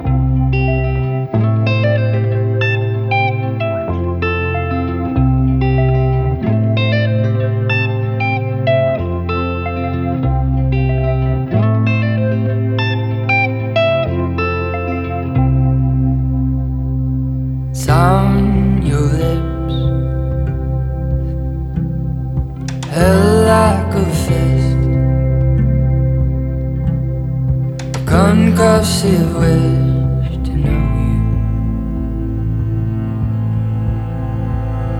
Жанр: Рок / Фолк-рок